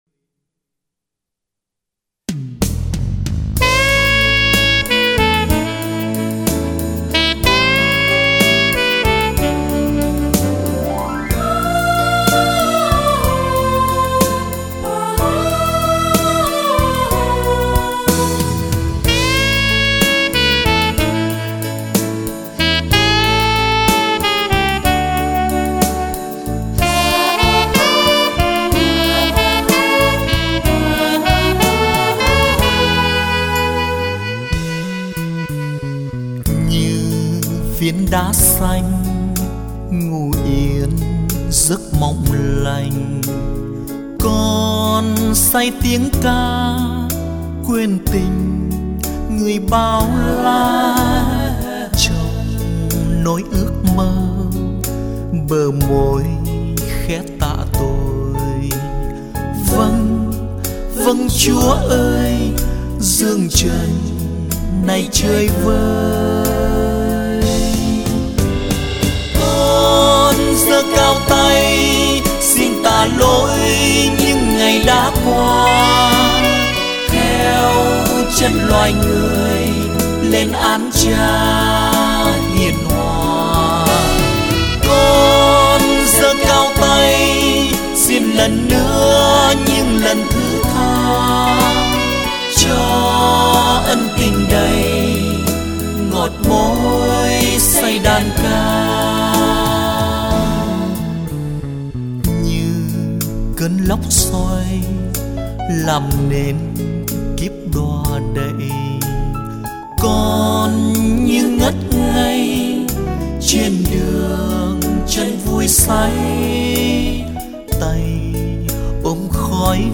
Thánh Ca